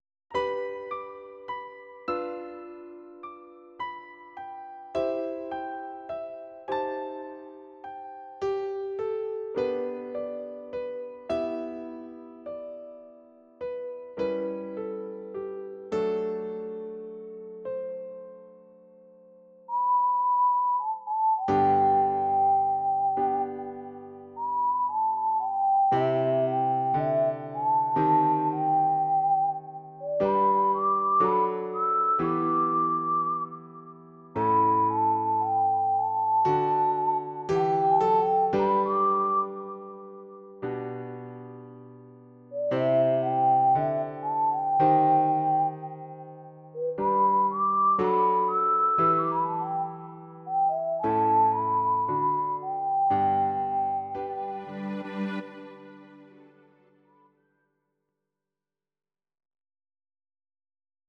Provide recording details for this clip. Audio Recordings based on Midi-files